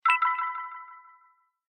dm_received.ogg